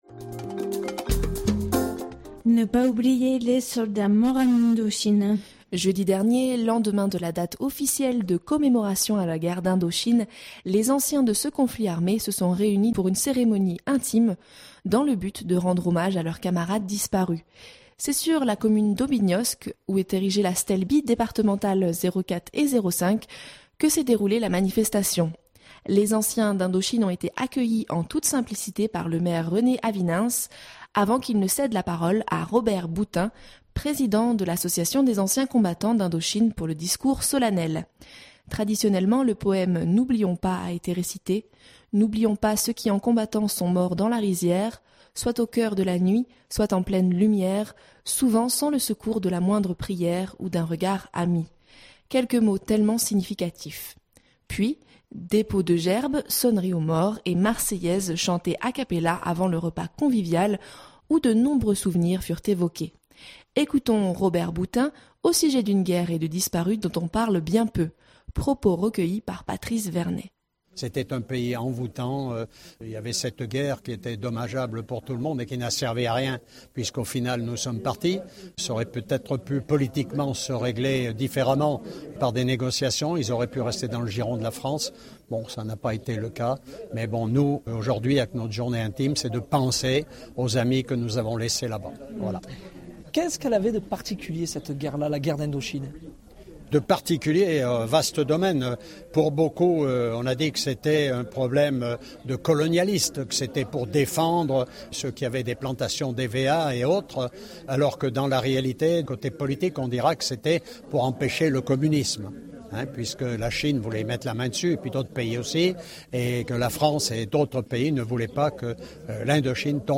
Jeudi dernier, lendemain de la date officielle de commémoration à la guerre d’Indochine, les anciens de ce conflit armé se sont réunis pour une cérémonie intime dans le but de rendre hommage à leurs camarades disparus. C’est sur la commune d’Aubignosc, où est érigée la stèle bi-départementale 04 et 05, que s’est déroulée la manifestation.